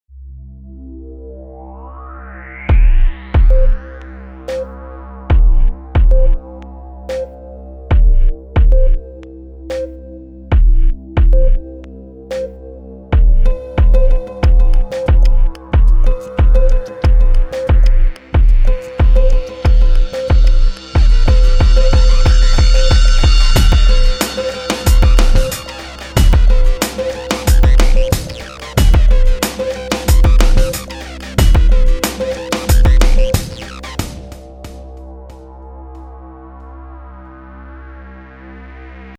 オリジナル iPhone 着信音
Logic Proと、アプリ「BeatMaker」で制作しています。